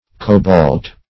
Cobalt \Co"balt\ (k[=o]"b[o^]lt; 277, 74), n. [G. kobalt, prob.